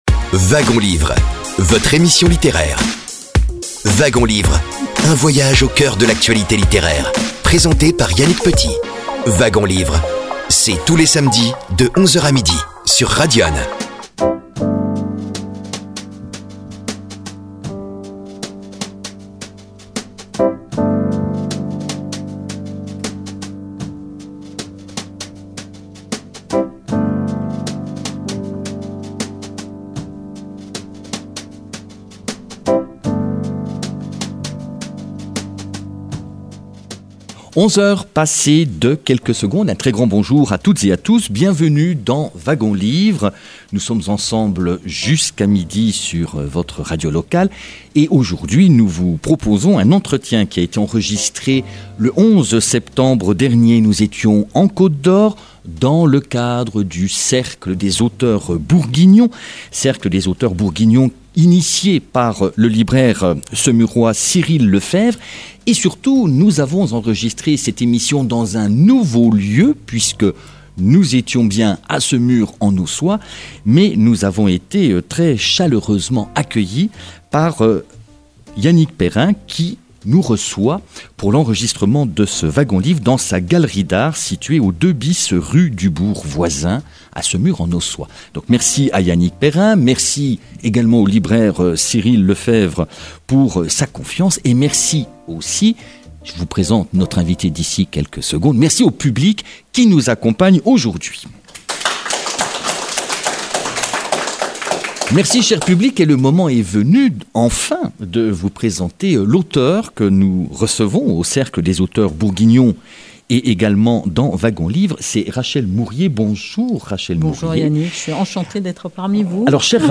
Entretien
Une émission enregistrée en public le 11 septembre 2025
à Semur-en-Auxois